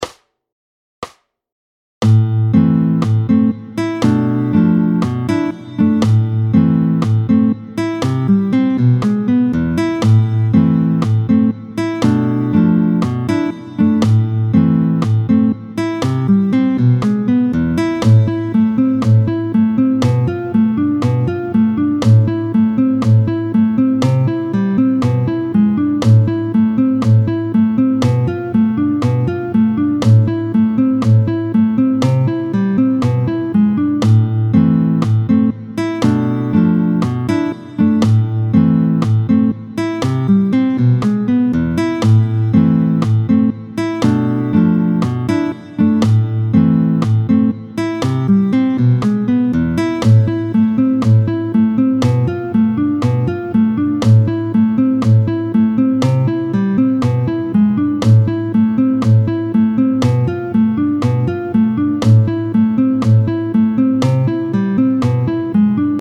20-02 Y’a d’la nostalgie dans l’air. Vite 2/2, tempo 120